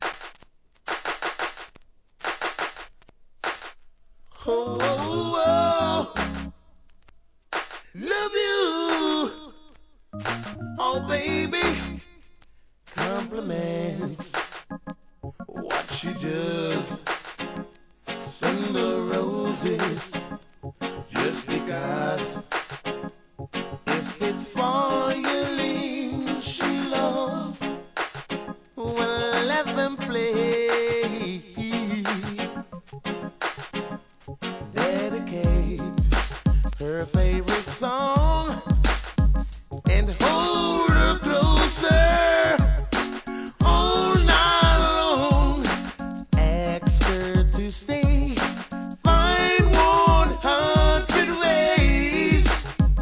REGGAE
HIP HOP MIX